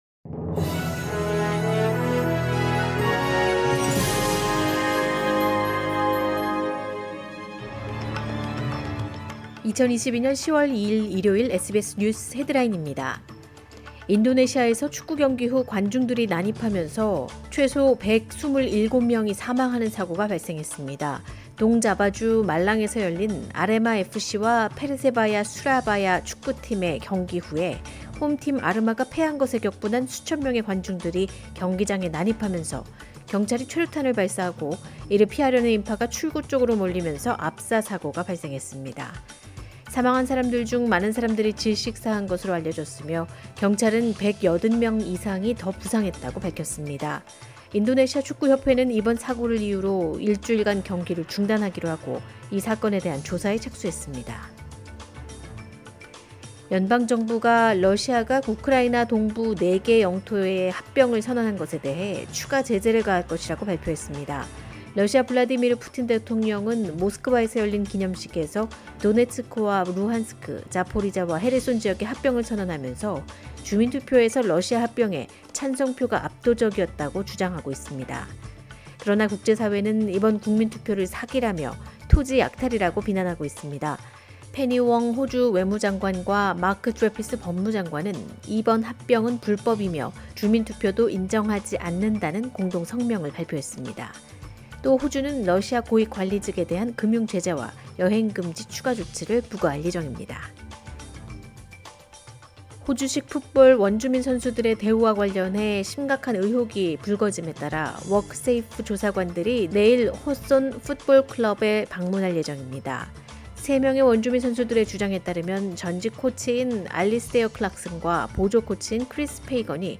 2022년 10월 2일 일요일 SBS 한국어 간추린 주요 뉴스입니다.